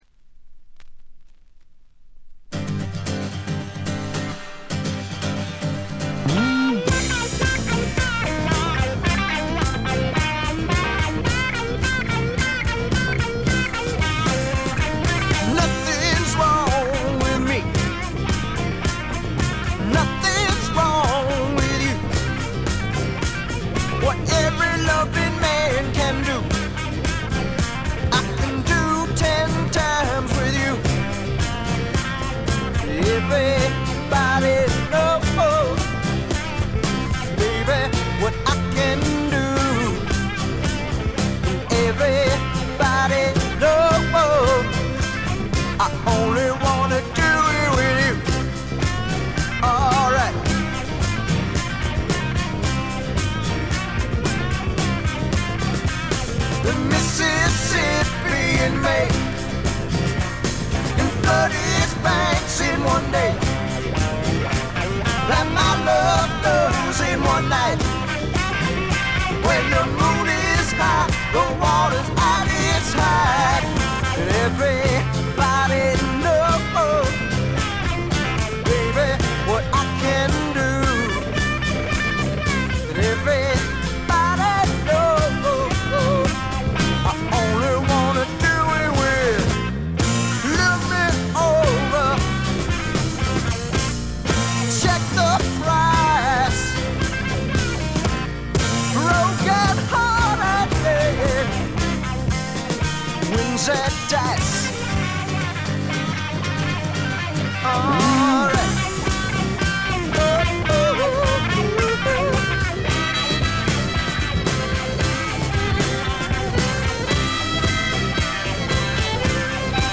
見た目に反して、全体にバックグラウンドノイズ、チリプチが多め。
基本は英国的としか言いようのない重厚で深い陰影のある哀愁のフォークロックです。
試聴曲は現品からの取り込み音源です。